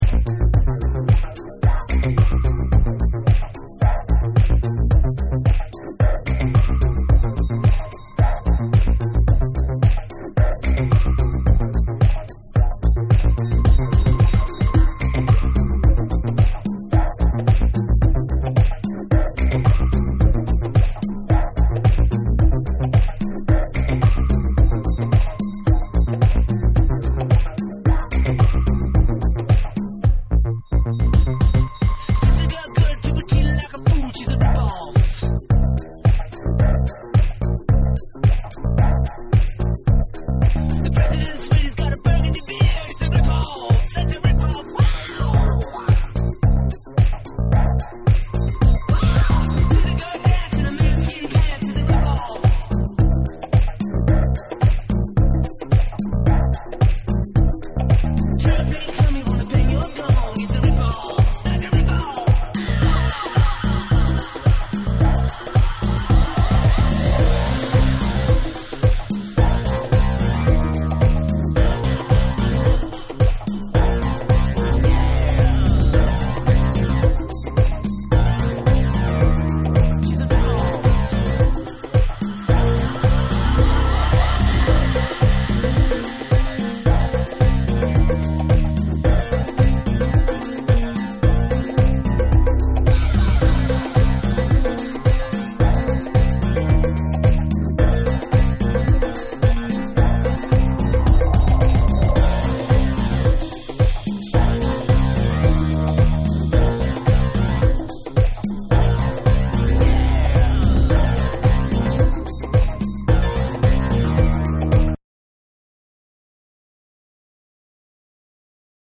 パーカッションの効いたエレクトロ・ブギーにシャウトするヴォーカルを被せた
NEW WAVE & ROCK / RE-EDIT / MASH UP